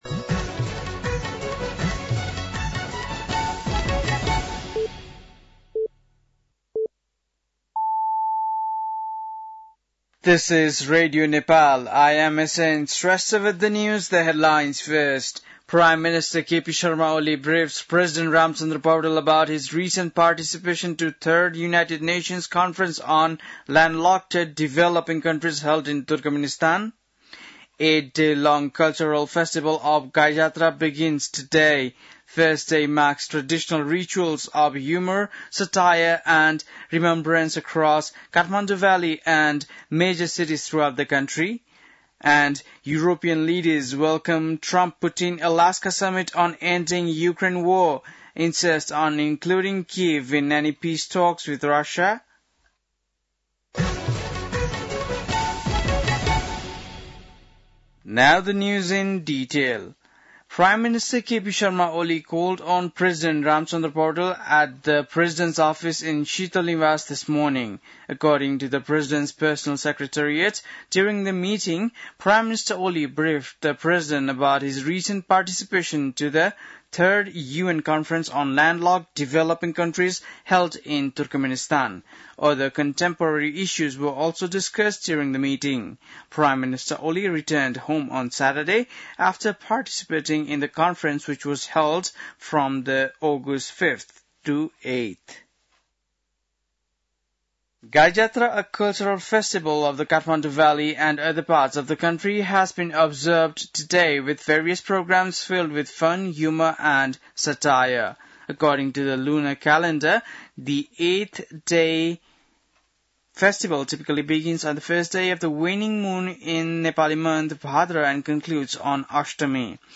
बेलुकी ८ बजेको अङ्ग्रेजी समाचार : २५ साउन , २०८२
8-pm-english-news-4-25.mp3